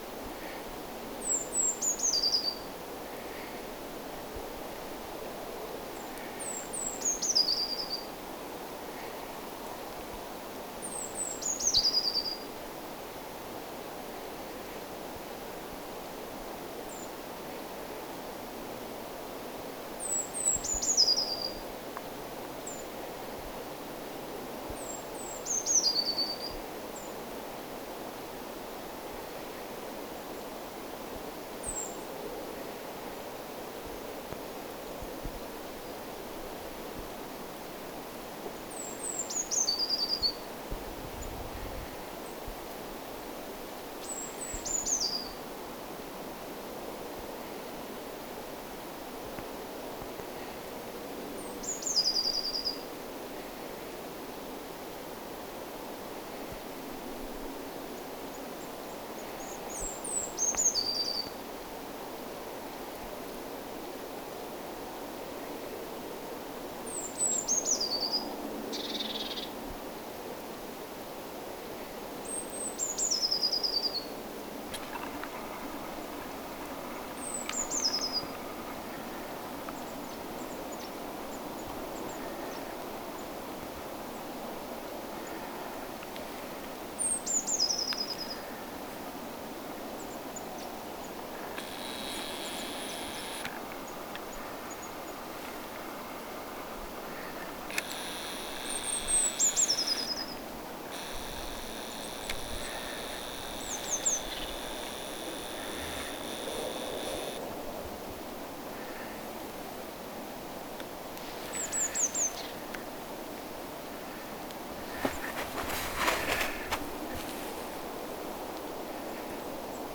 sinitiainen laulaa
sinitiainen_laulaa.mp3